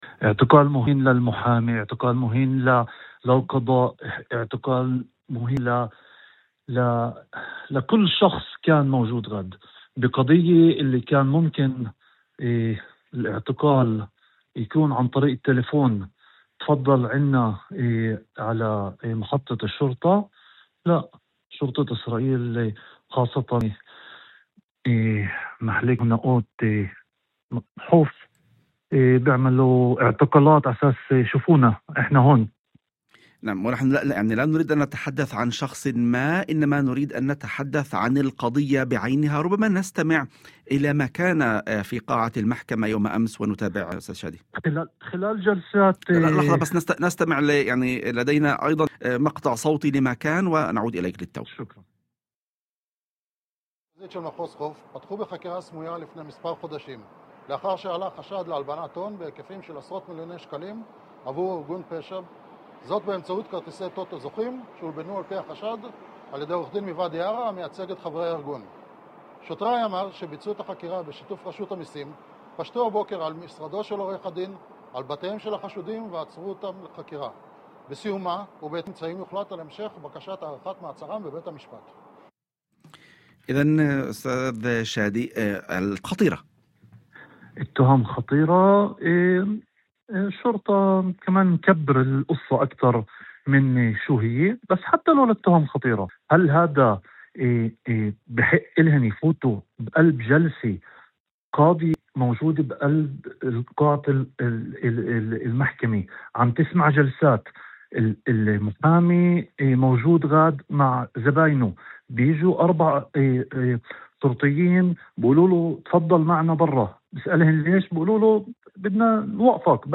وأضاف في مداخلة هاتفية ضمن برنامج "يوم جديد"، على إذاعة الشمس، أن الشرطة دخلت القاعة أثناء انعقاد الجلسة وطلبت من المحامي مغادرتها بالقوة دون تنسيق مسبق أو إذن من المحكمة.